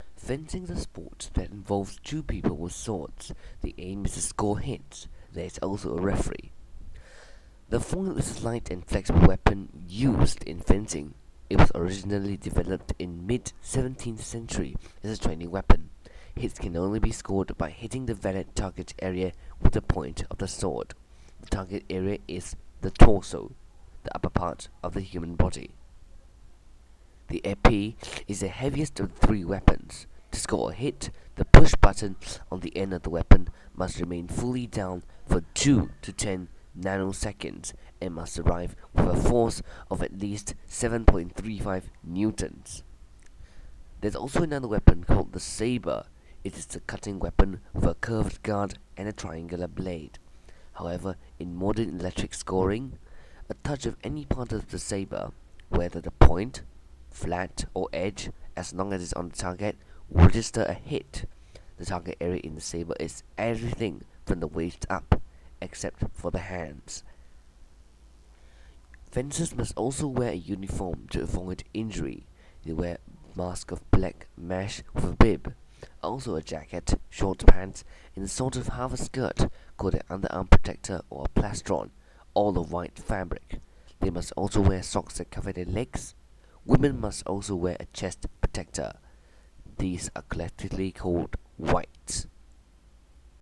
Fencing, the narration of the article.